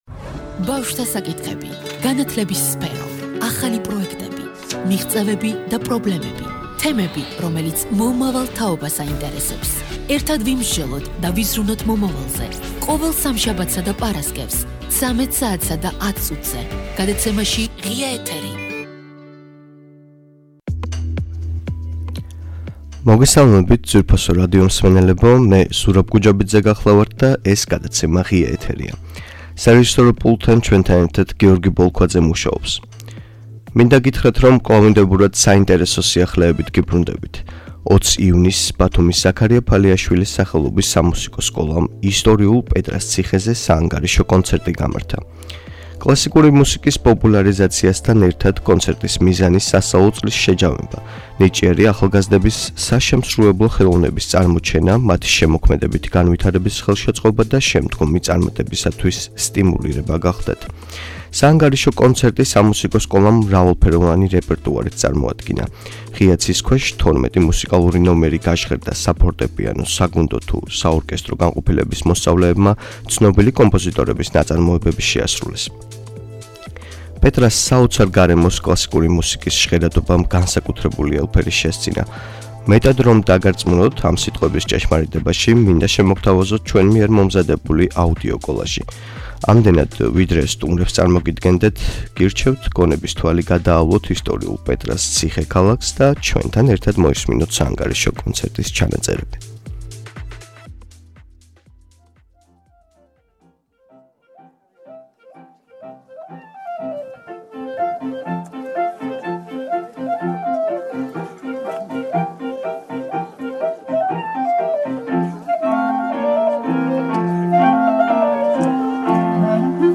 საანგარიშო კონცერტი პეტრას ციხეზე - ბათუმის ზაქარია ფალიაშვილის სახელობის სამუსიკო სკოლა, შედეგები, პერსპექტივები .